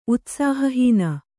♪ utsāhahīna